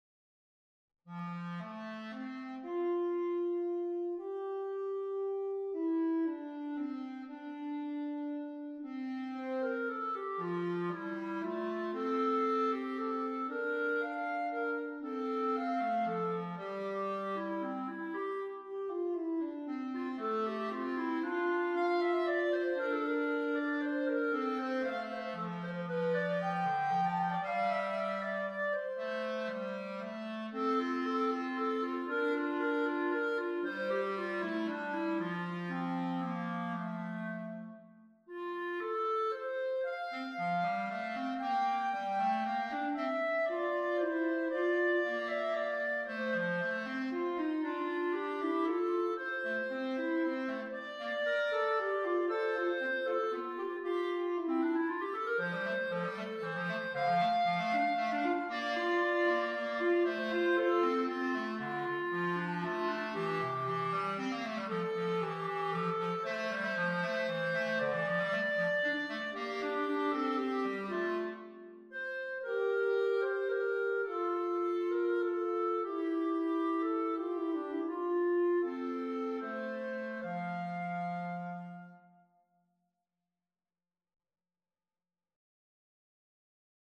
clarinet and bass clarinet duet